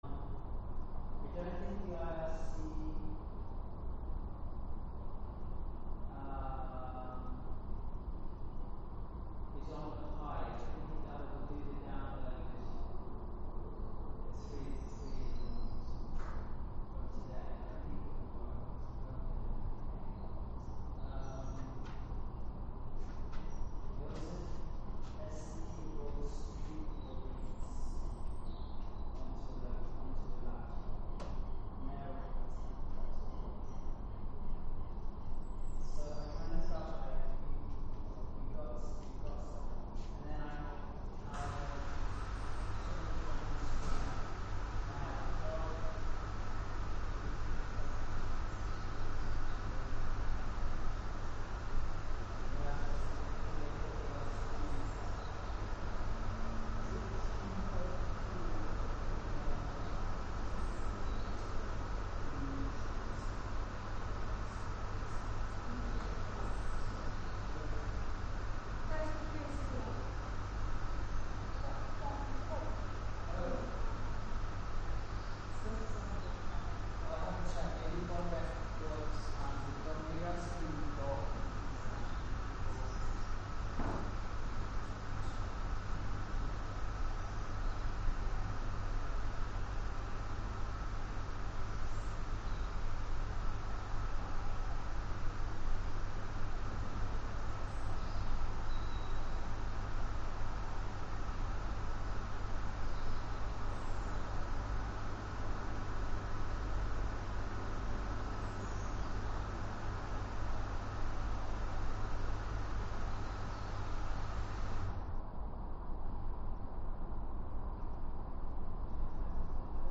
Wave Farm | Live from Soundcamp: soundcamp radio